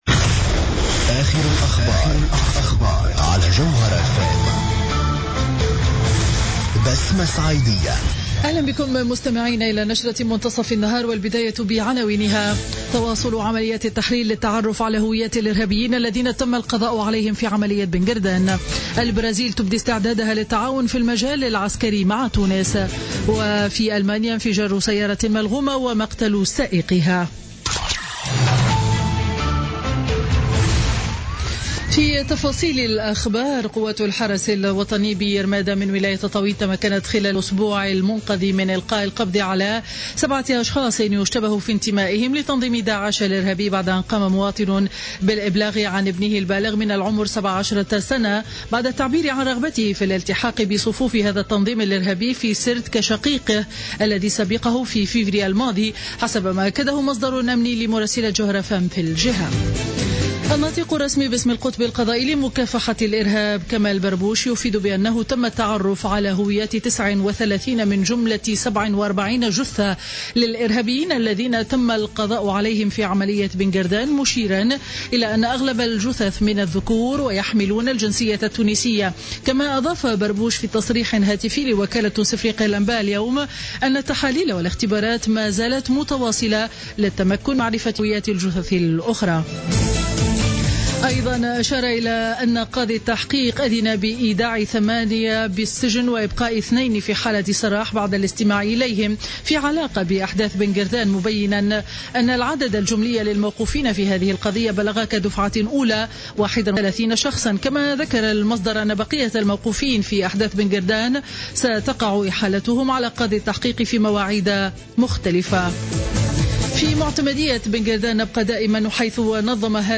نشرة أخبار منتصف النهار ليوم الثلاثاء 15 مارس 2016